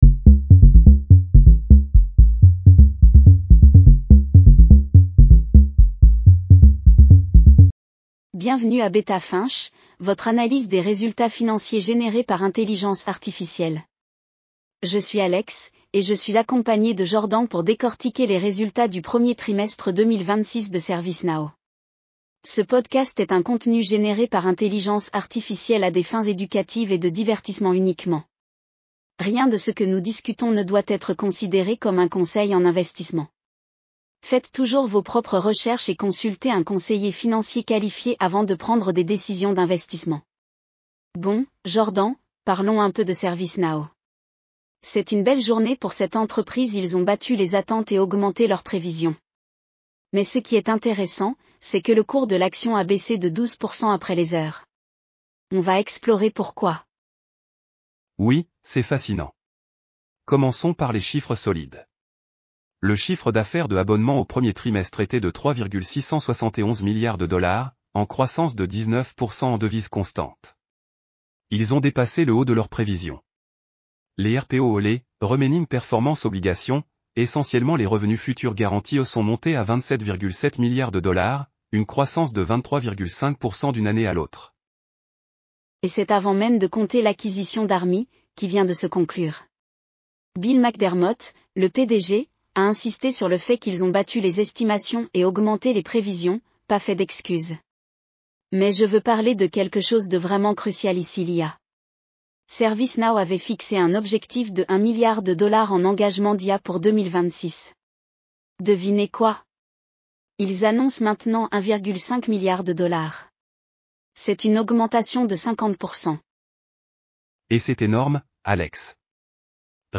ServiceNow Q1 2026 earnings call breakdown.
Bienvenue à Beta Finch, votre analyse des résultats financiers générée par intelligence artificielle.